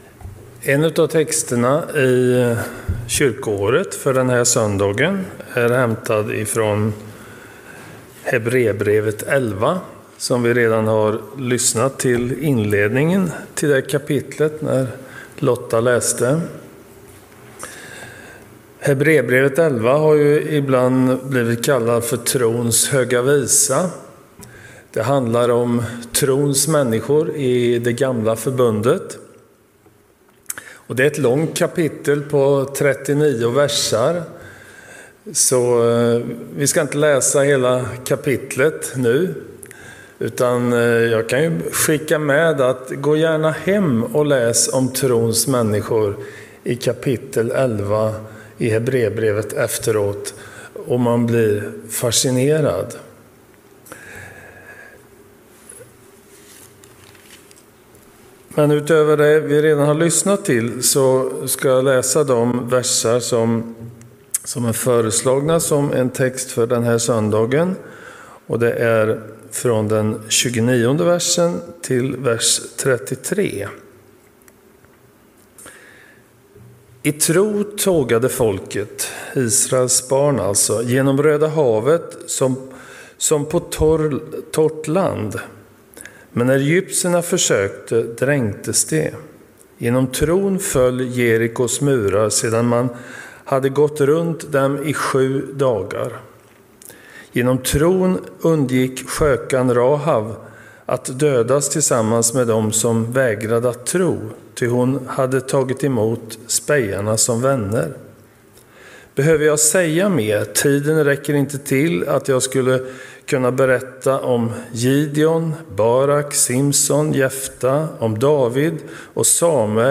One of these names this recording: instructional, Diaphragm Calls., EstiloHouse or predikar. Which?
predikar